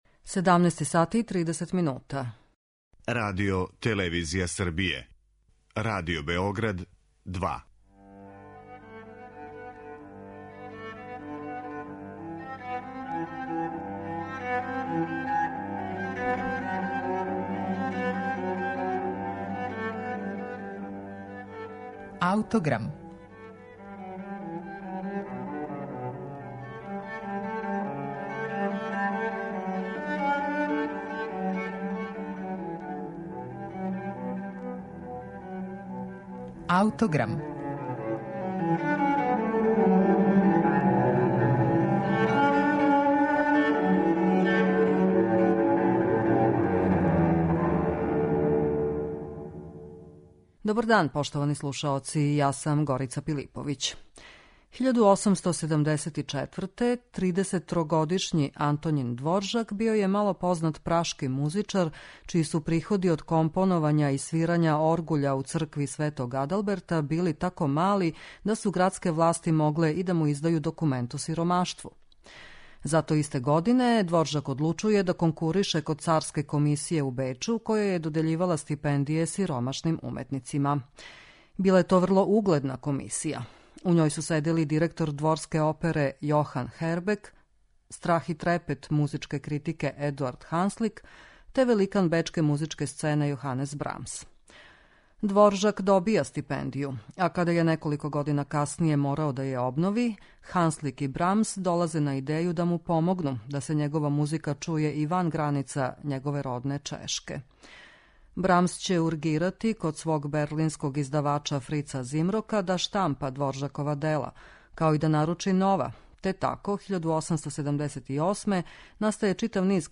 Сметанин Гудачки секстет оп. 48 у А-дуру